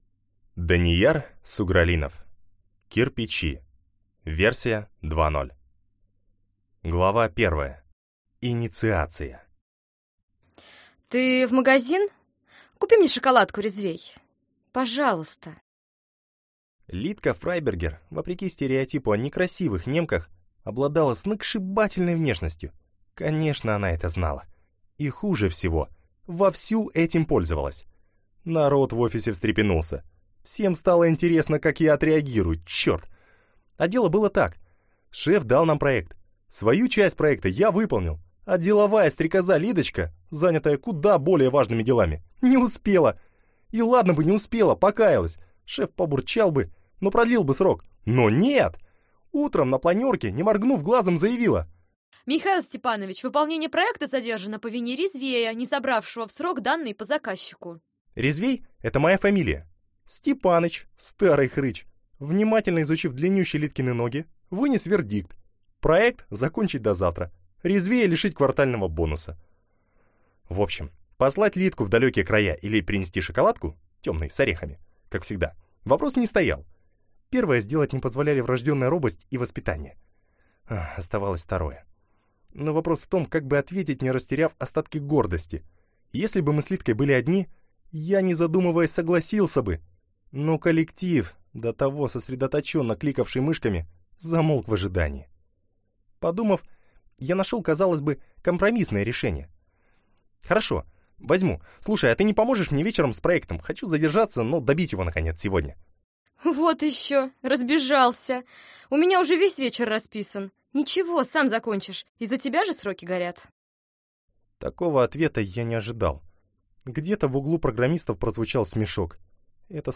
Аудиокнига Кирпичи 2.0 - купить, скачать и слушать онлайн | КнигоПоиск